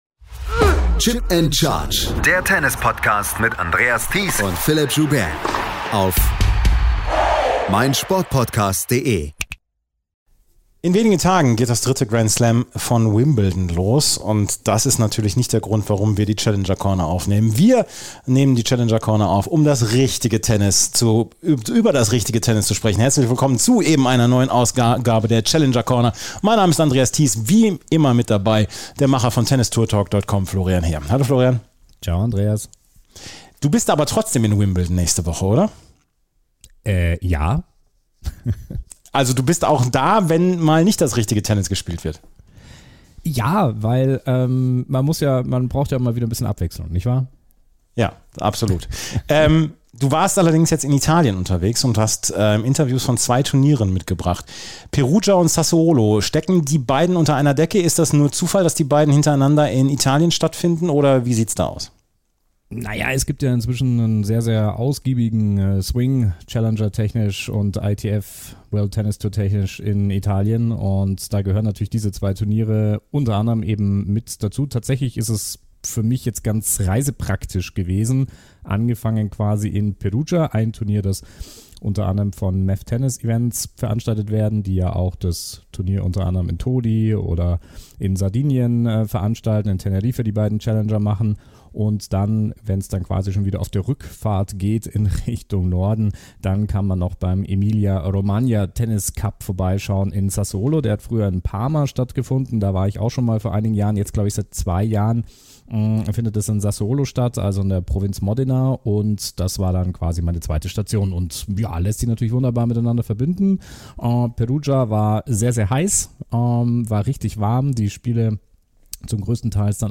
war in Perugia und Sassuolo vor Ort und hat wieder viele Interviews mitgebracht.